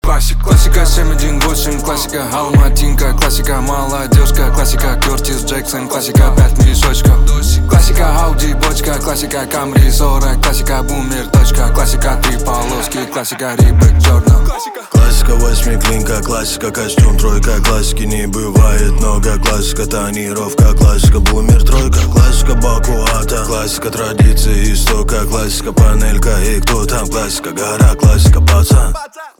русский рок
битовые , басы , качающие